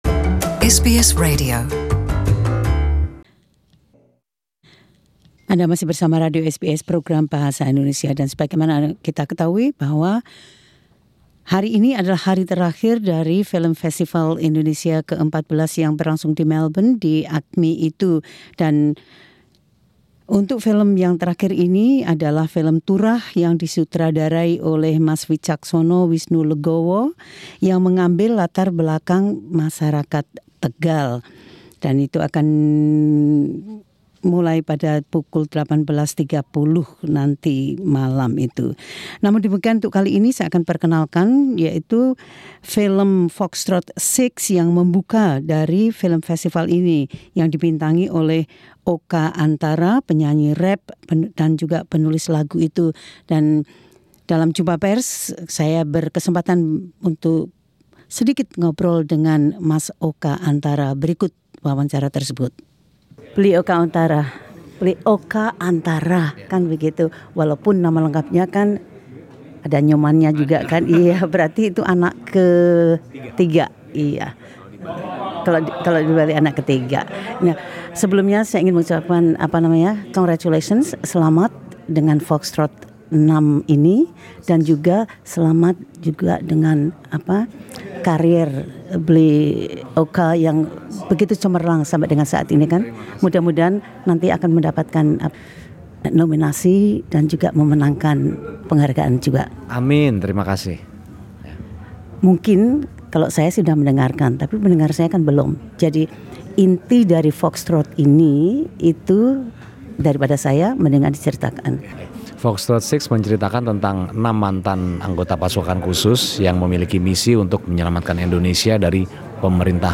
Oka Antara (Nyoman Oka Wisnupada Antara), aktor, rapper dan bintang film sci-fi action Foxtrot 6, berbicara tentang mengapa keterlibatan dalam film itu begitu istimewa baginya.